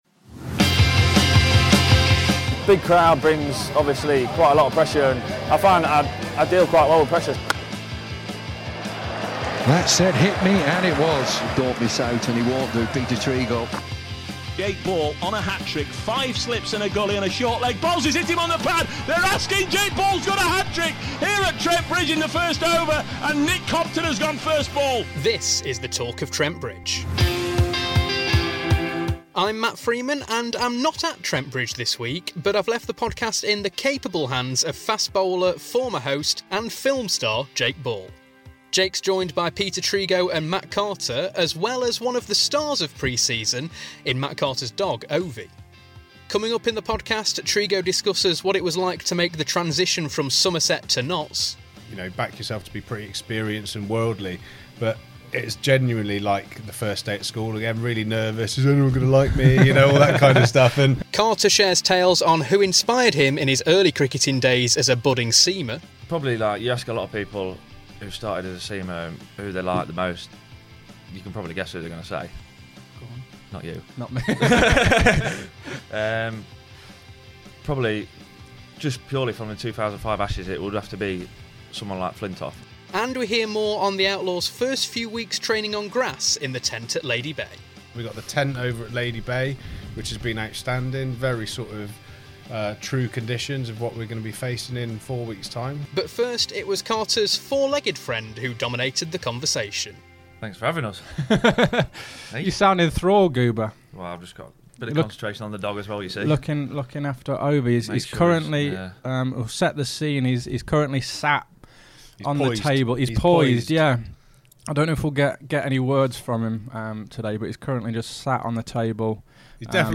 The trio talk about their love of dogs, what it's like in the Outlaws' newest training facility, the marquee at Notts Sports Ground, and their cricketing inspirations when they were youngsters. Listen to see if the trio can keep their focus as Ovie roams around the studio causing mischief, and a few interruptions.